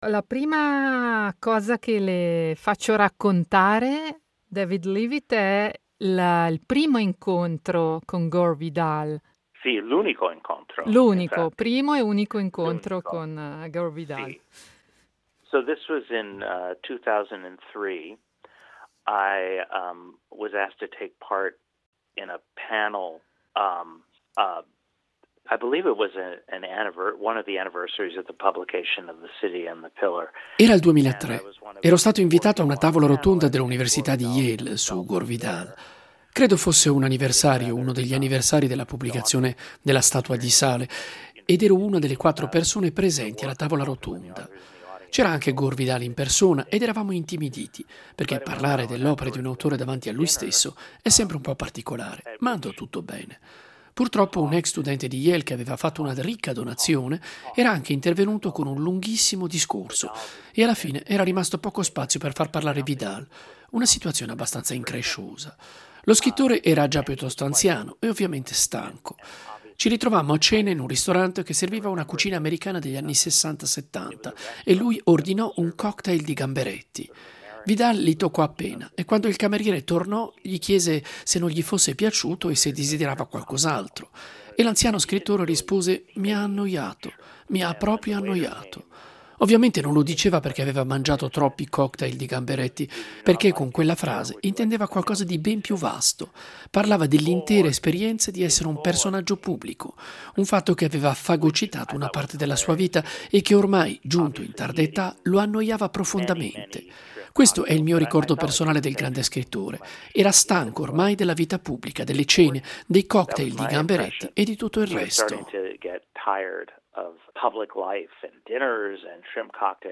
Proprio per questo vogliamo festeggiare il compleanno di Gore Vidal e il suo libro “scandaloso” con un viaggio nella letteratura gay, dall’America all’Italia, nel corso degli ultimi settant’anni. Siamo partiti con un’importante intervista a David Leavitt , professore di letteratura americana all’Università della Florida, autore di alcuni romanzi centrali per la letteratura Usa degli anni 80, come Pranzo di Famiglia o La lingua perduta delle gru.